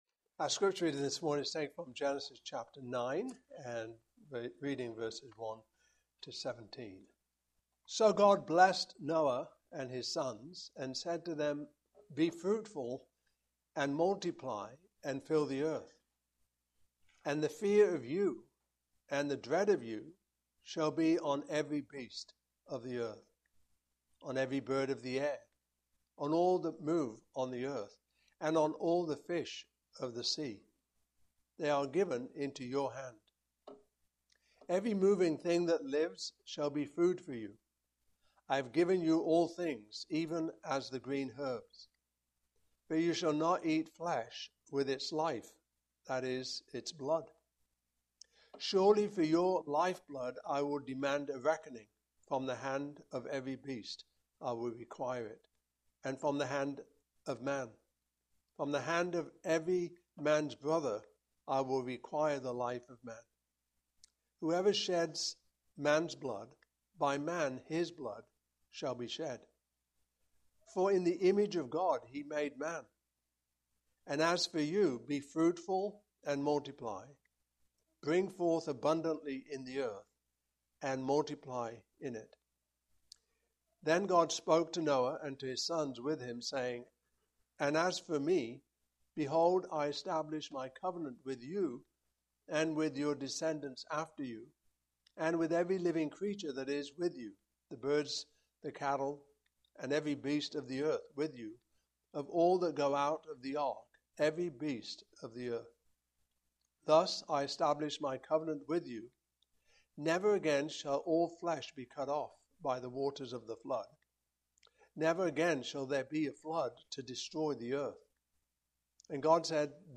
Passage: Genesis 9:1-17 Service Type: Morning Service